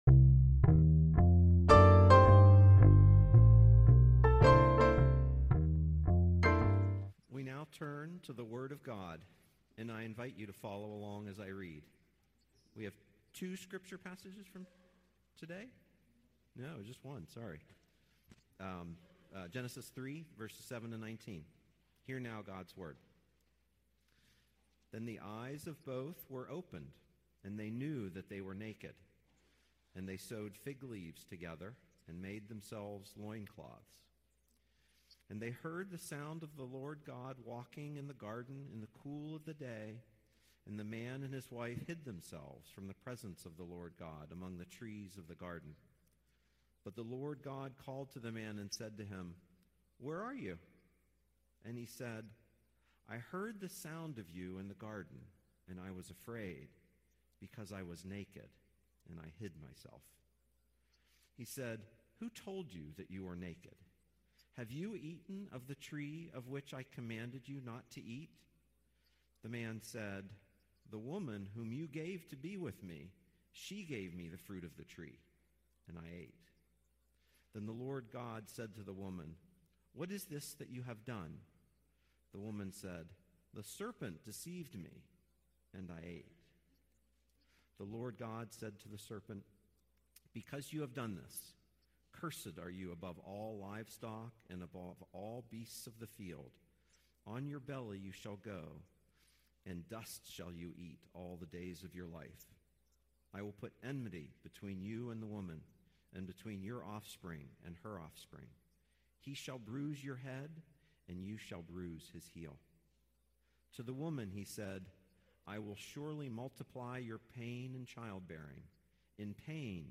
Passage: Genesis 3:7-19 Service Type: Sunday Worship